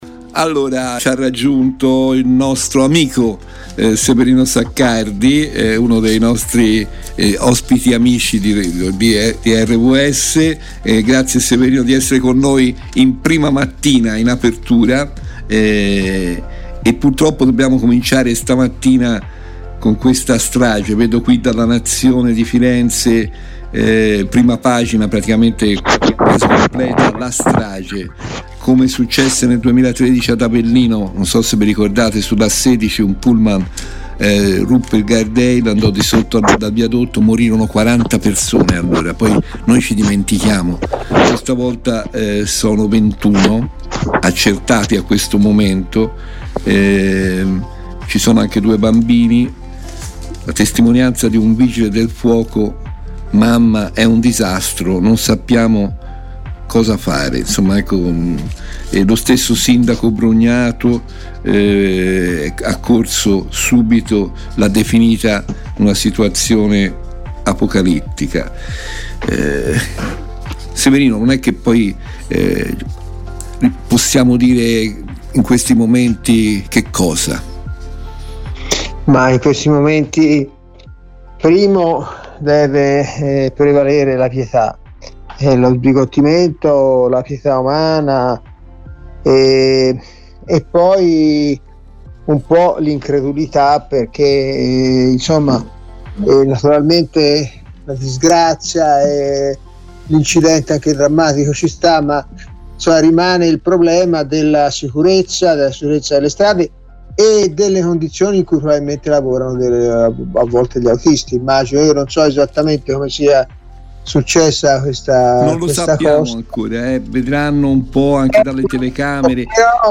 Nel corso della diretta RVS del 04 ottobre 2023